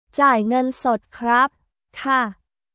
ジャイ ゲン ソット クラップ／カー